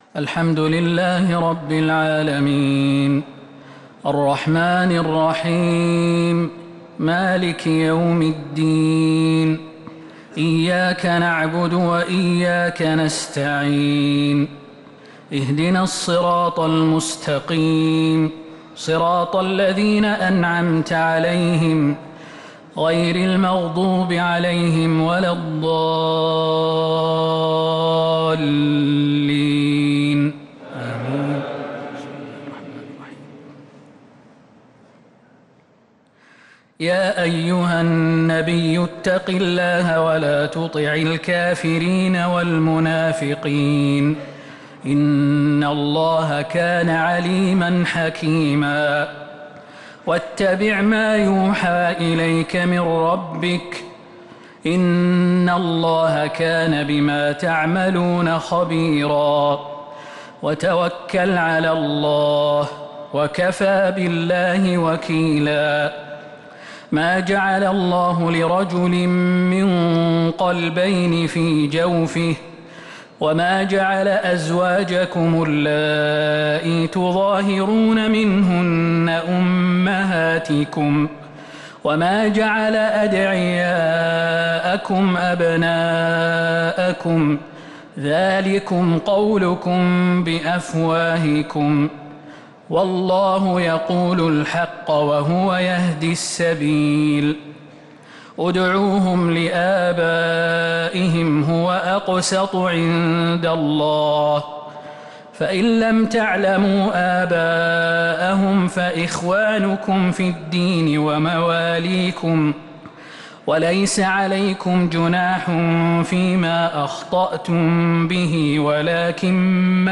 تراويح ليلة 24 رمضان 1447هـ فواتح الأحزاب (1-48) | taraweeh 24th night Ramadan 1447H surah Al-Ahzab > تراويح الحرم النبوي عام 1447 🕌 > التراويح - تلاوات الحرمين